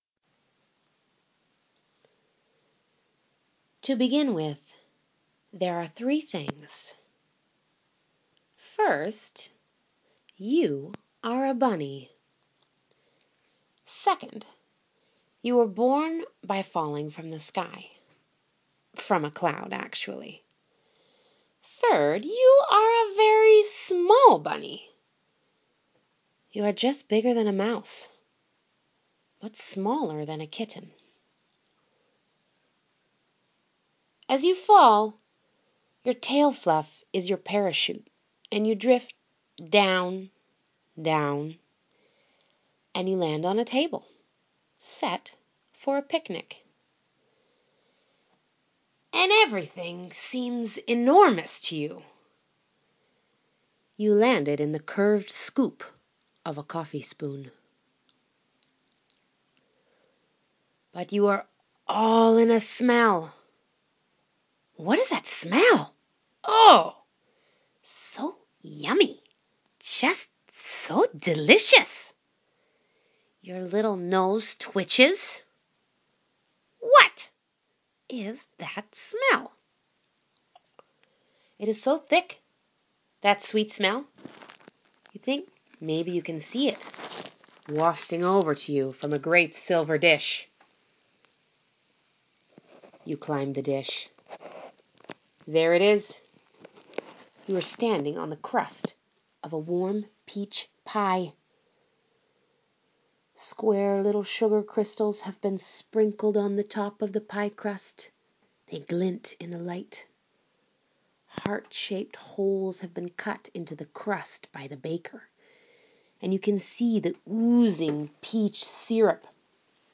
Audio: These stories are best for listening only, ideally in bed on a rainy evening……….
Bunny`s Sleep Picnic Bunny`s Sleep Picnic is a drowsy romp.